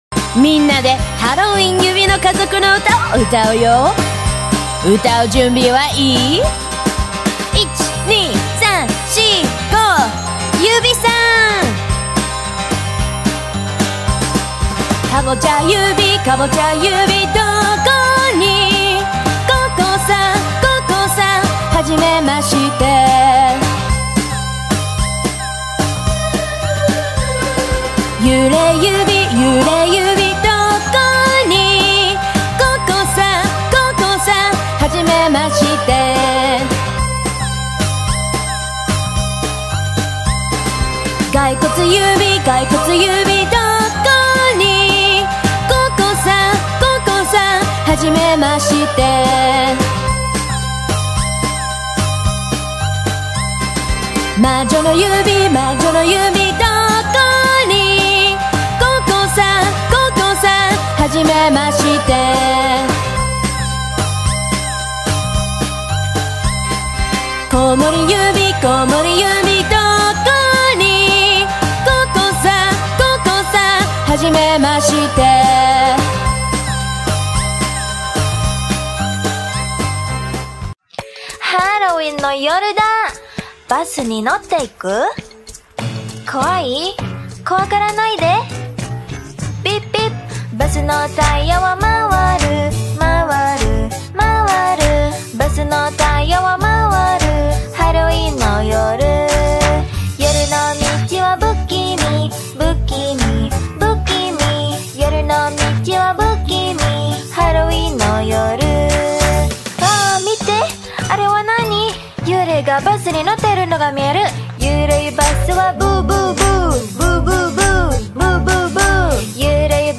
اهنگ هالووین کودکانه